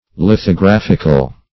\lith`o*graph"ic*al\, a. [Cf. F. lithographique.]